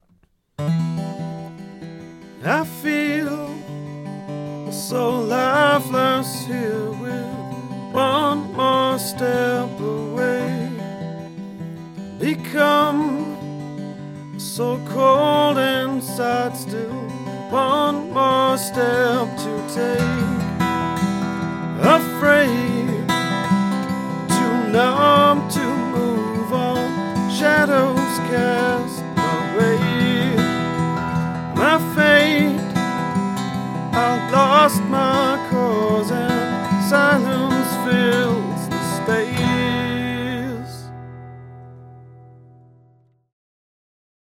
Audiobeispiele: Singer-Songwriter aufnehmen
Off-Axis 15°
Akustikgitarre aufnehmen